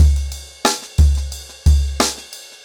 InDaHouse-90BPM.9.wav